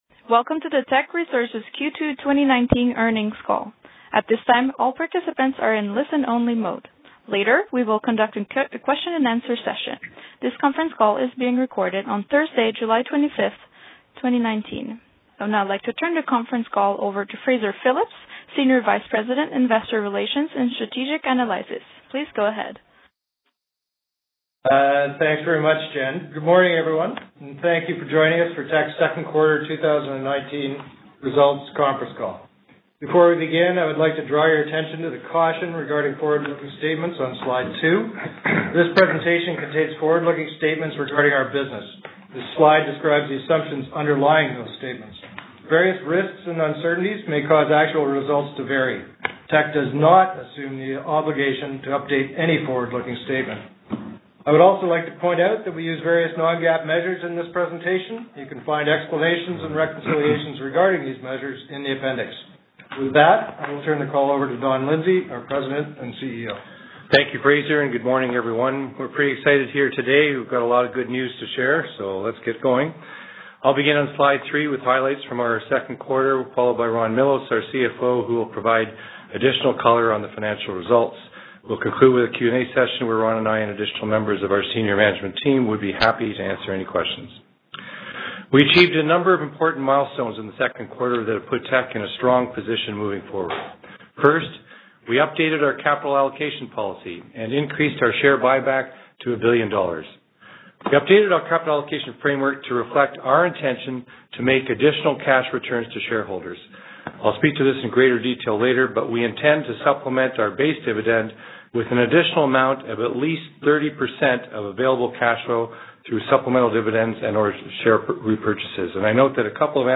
Q2-2019-Conference-Call-Audio.mp3